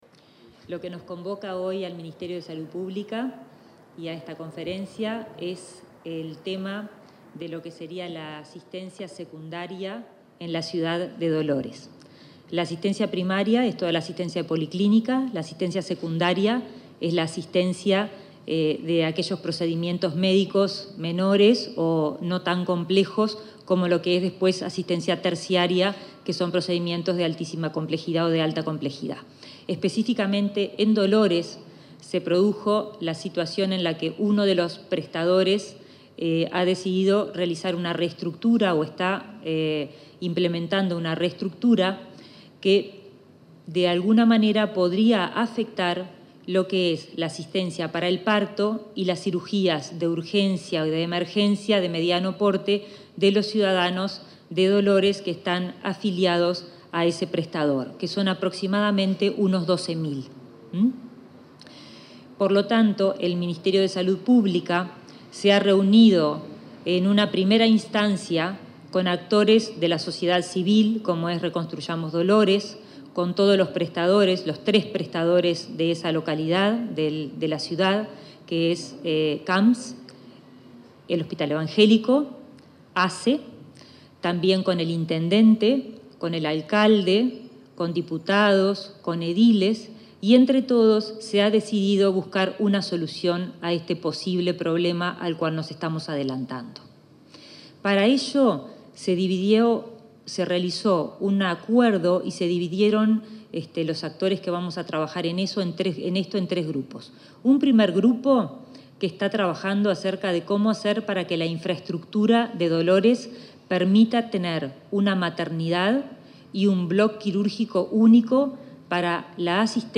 Conferencia de prensa en el Ministerio de Salud Pública
La directora general de Coordinación del Ministerio de Salud Pública, Karina Rando, y el presidente de la Junta Nacional de Salud, Alberto Yagoda,